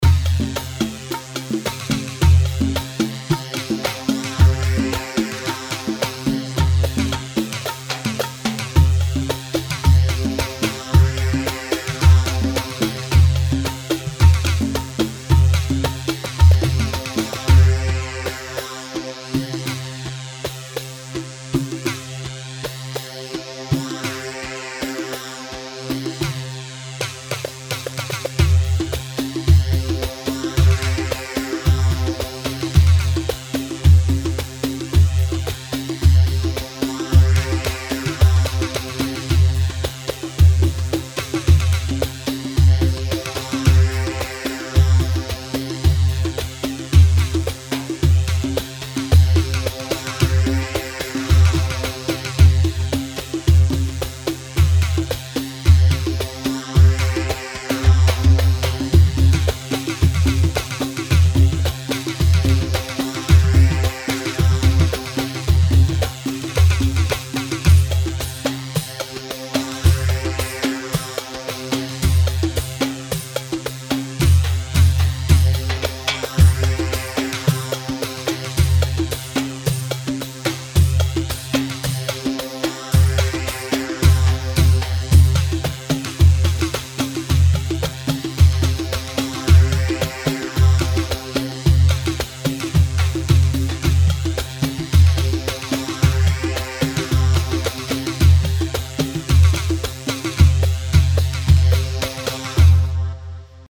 Hewa 4/4 110 هيوا
Hewa-110-mix.mp3